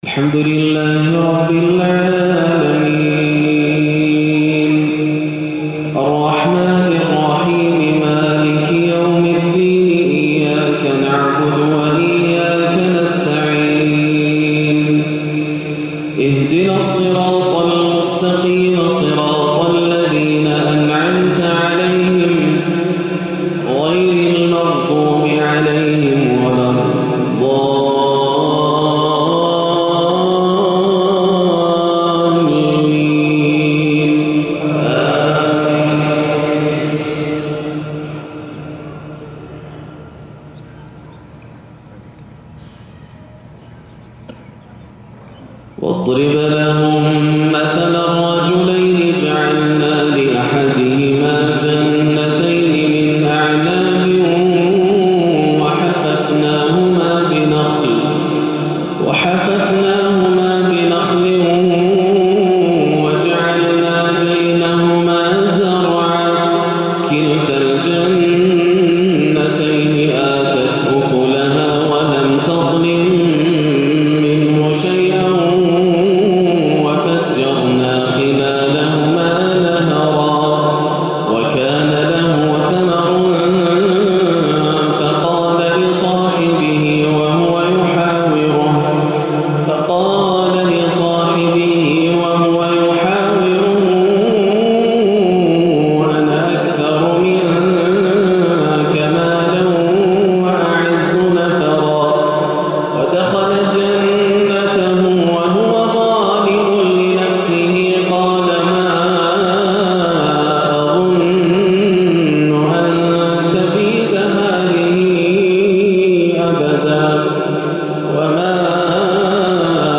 عشاء الثلاثاء 9-4-1437هـ من سورة الكهف 32-50 > عام 1437 > الفروض - تلاوات ياسر الدوسري